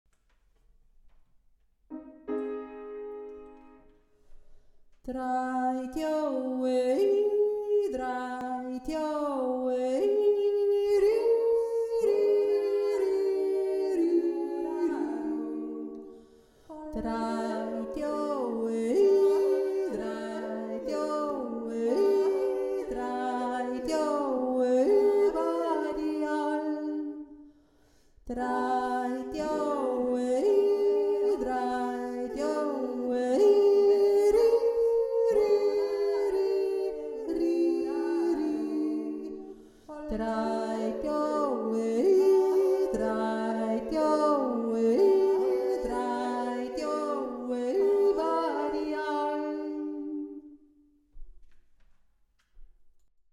die erste Stimme